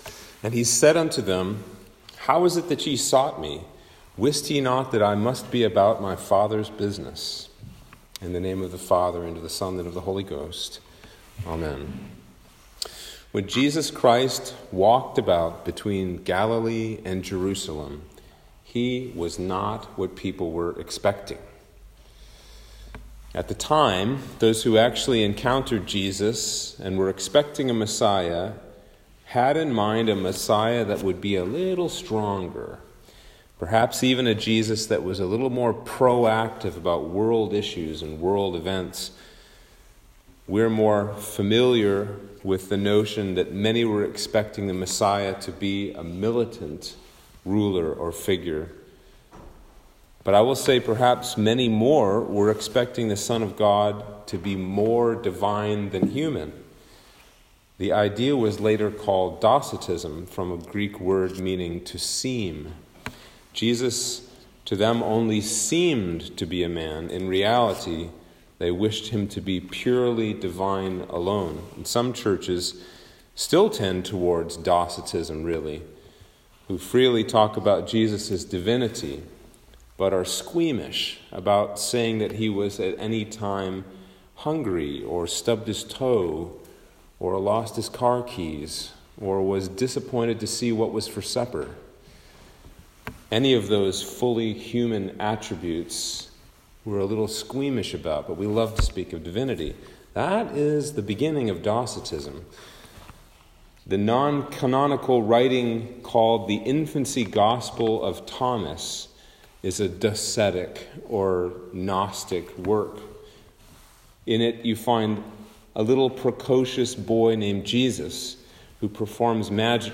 Sermon for Epiphany 1 - 2022